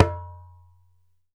ASHIKO 4 00R.wav